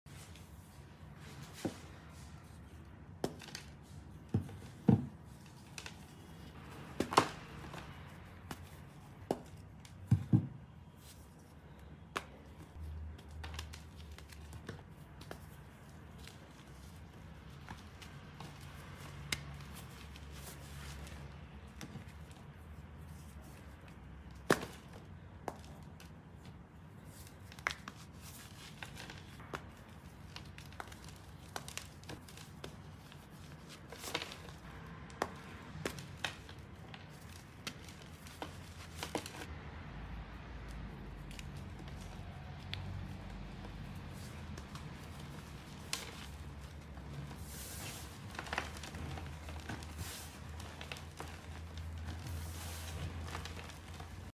Hard chalk